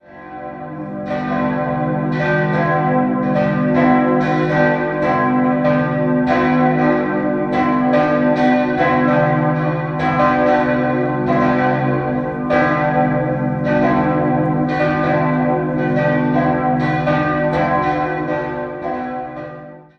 3-stimmiges Geläute: c'-es'-g' Die drei Glocken des Hauptgeläuts wurden im Jahr 1872 von Hermann Große gegossen.